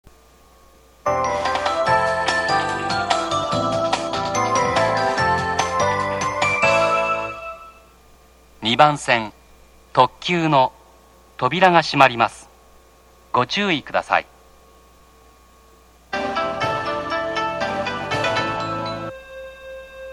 鴨東線 出町柳 ▼この駅では放送後に短いメロディがあります。
1番線K特急発車放送
demachiyanagi-track1-d_lex.mp3